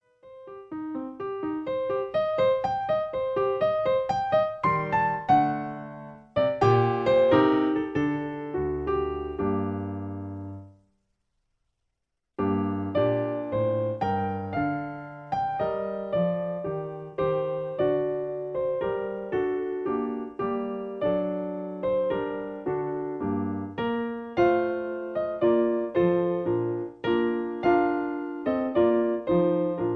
Original Key (G) Piano Accompaniment